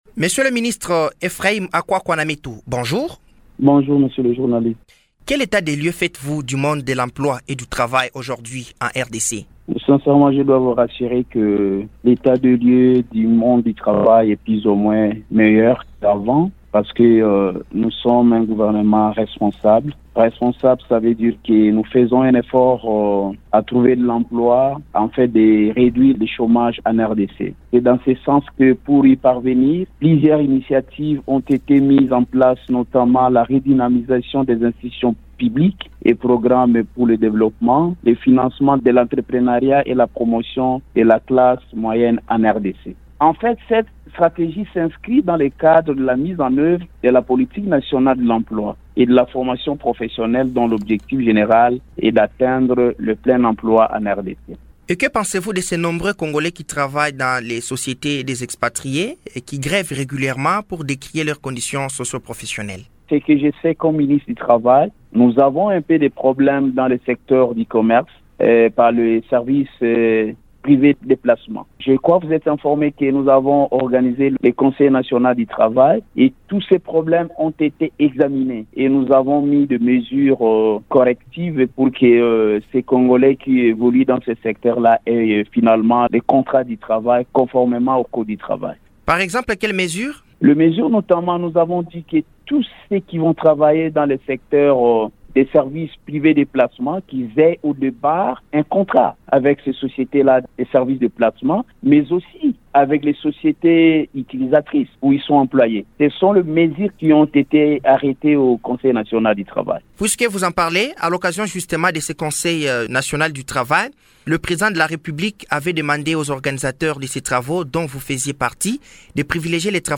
Lors d’une intervention sur Radio Okapi, ce membre du gouvernement a assuré que plusieurs dispositions ont été mises en œuvre pour améliorer les conditions professionnelles des travailleurs et lutter contre le chômage dans le pays.